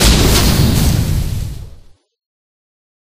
Fire9.ogg